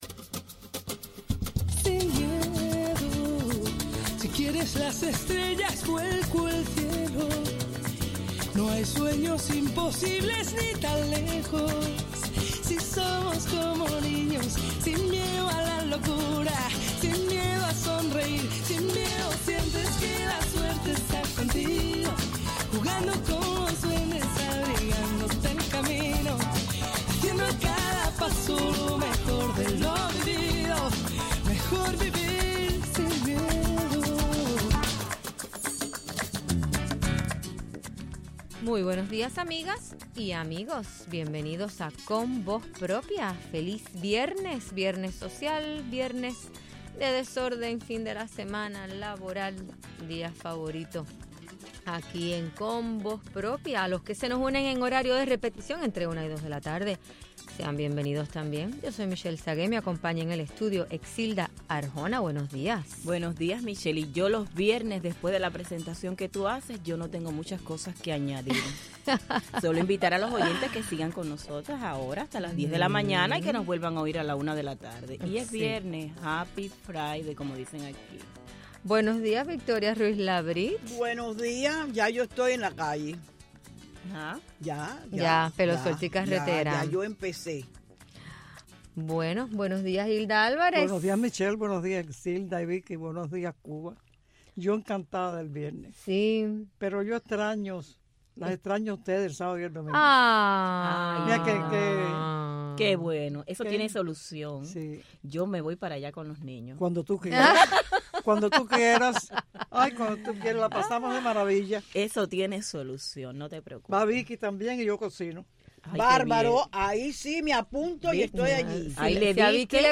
Abrimos nuestros microfonos a felicitaciones por el Dia de las Madres